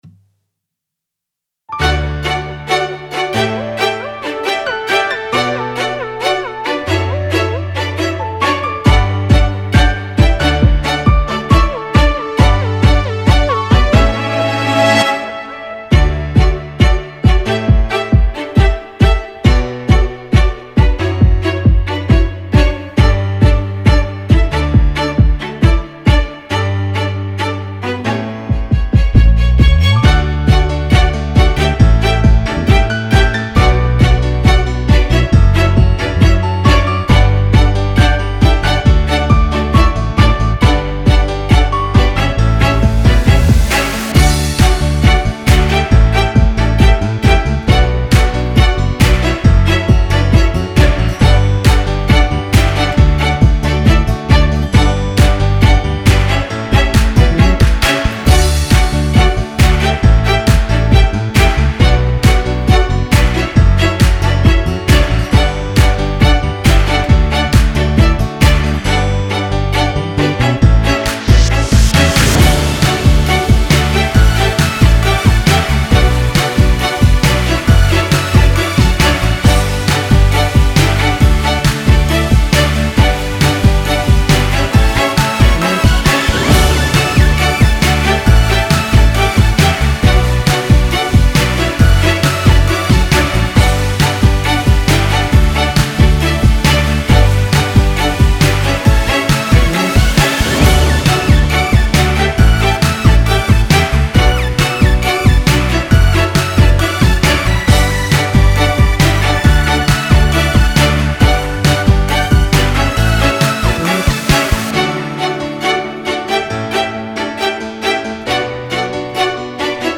演唱伴奏